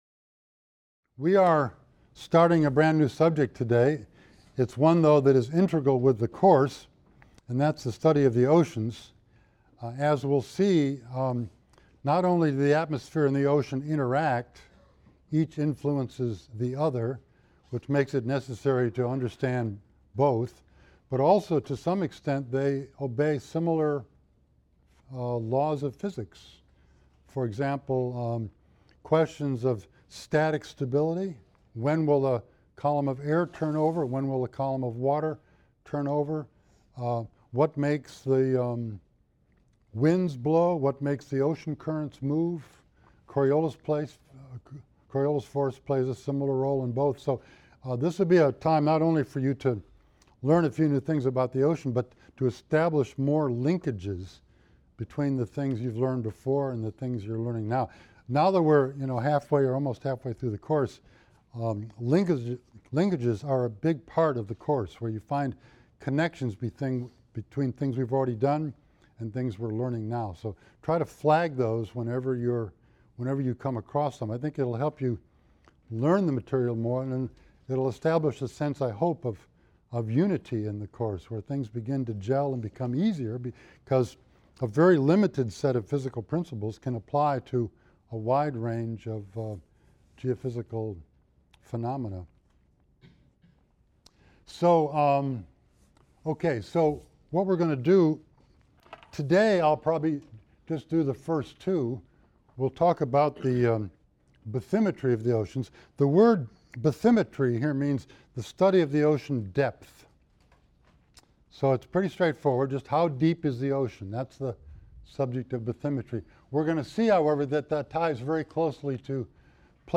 GG 140 - Lecture 19 - Ocean Bathymetry and Water Properties | Open Yale Courses